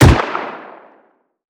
CookoffSounds / shotrocket / mid_1.wav
Cookoff - Improve ammo detonation sounds